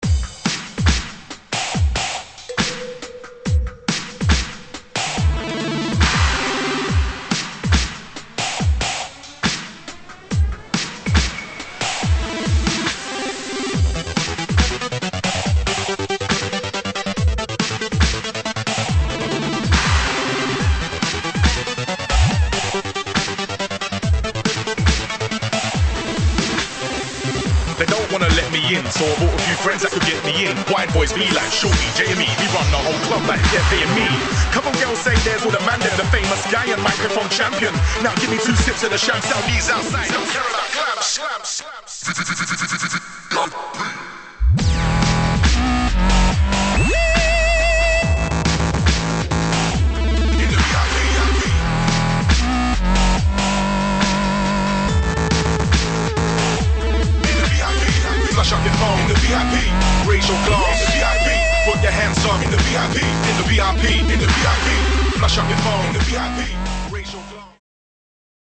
[ HOUSE | UKG | DUBSTEP ]